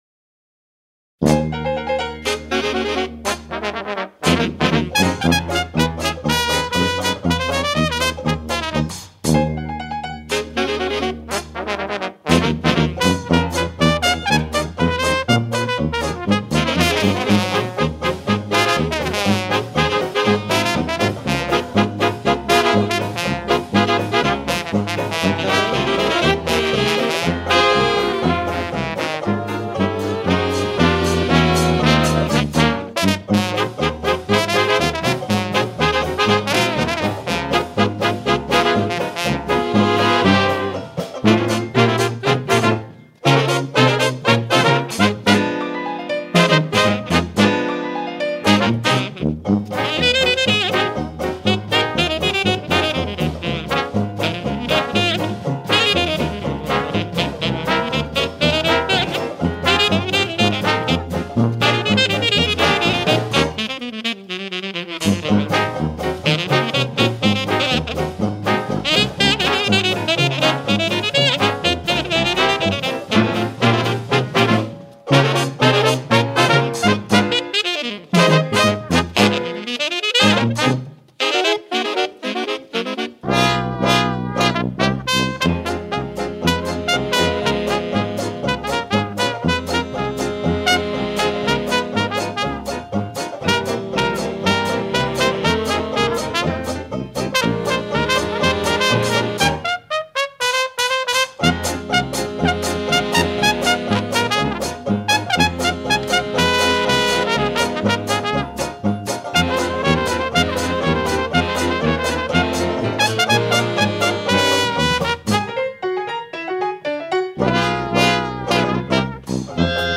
Trompettes
Saxophones et clarinettes
Trombone
Piano
Banjo
Tuba
Batterie
chant